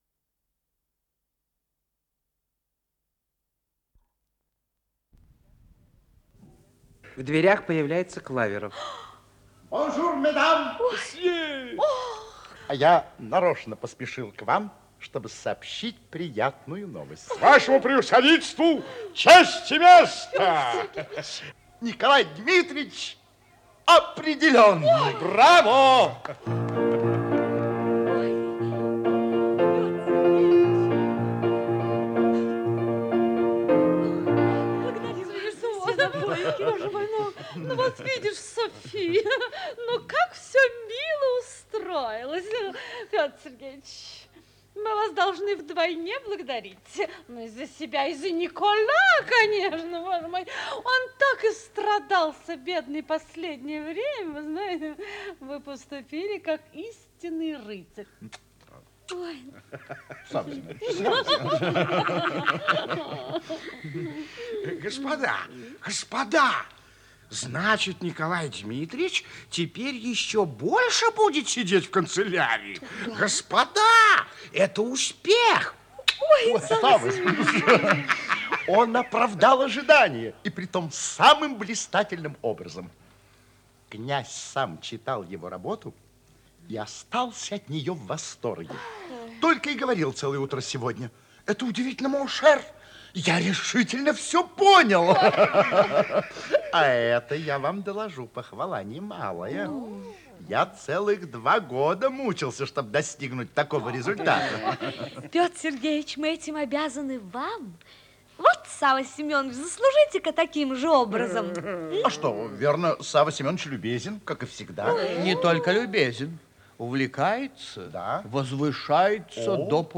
Исполнитель: Артисты государственного ленинградского нового театра